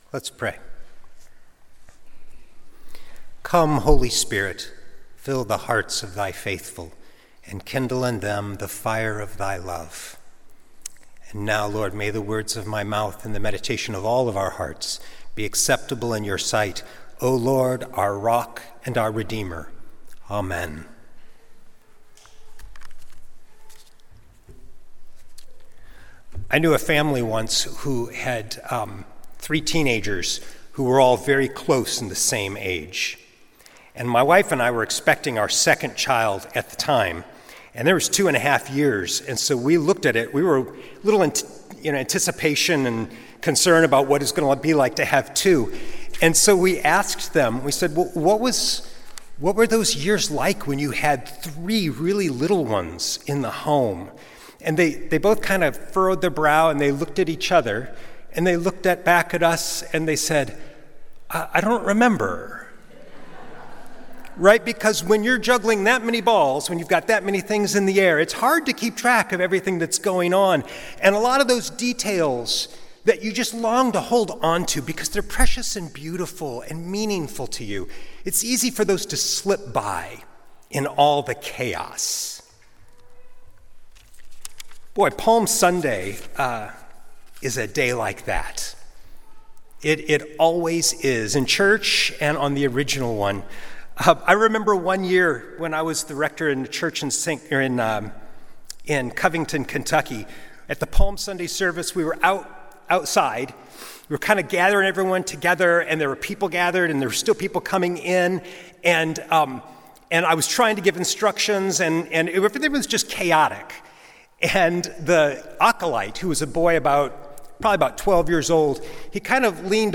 Sunday Worship–April 13, 2025
Sermons